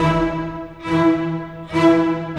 Rock-Pop 20 Bass, Cello _ Viola 03.wav